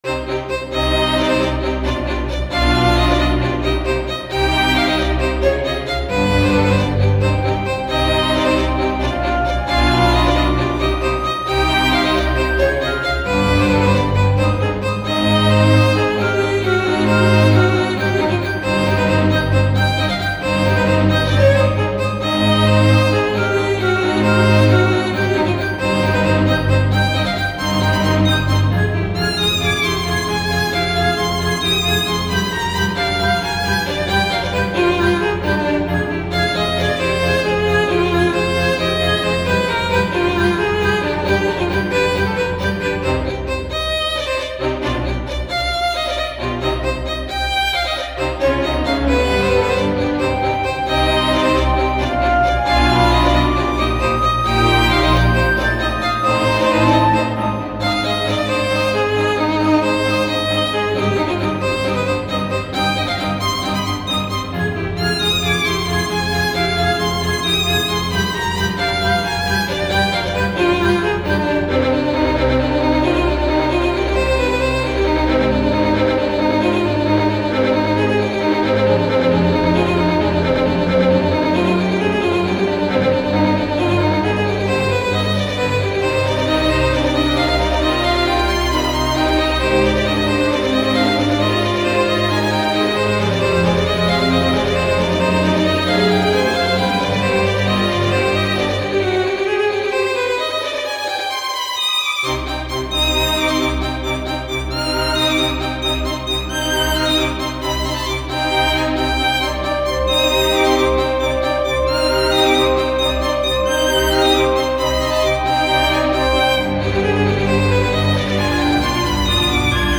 ogg(R) ゴシック バイオリン 弦楽
勇壮で悲しげなバイオリン。